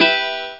Guitar(e Min Sound Effect
guitar-e-min.mp3